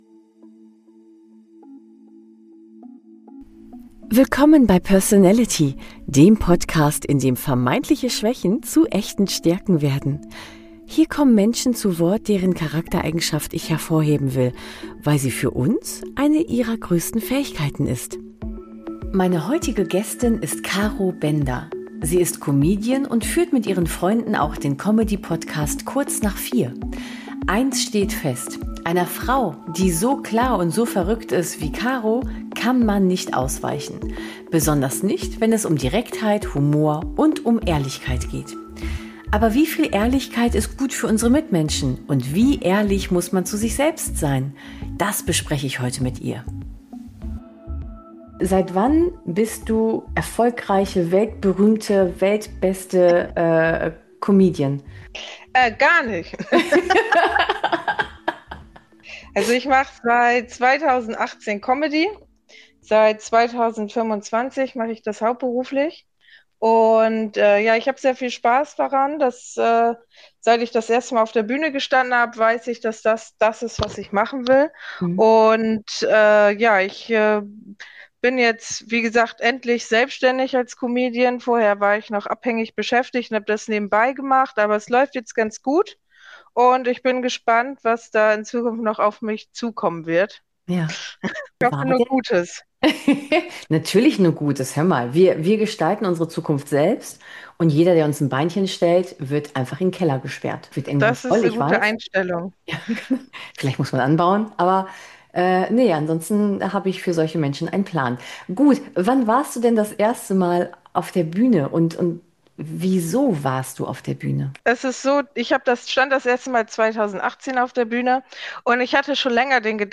Ein Gespräch über Gefühle, Humor, Selbstannahme und darüber, warum Perfektion überschätzt ist und Haltung oft leiser daherkommt, als wir denken.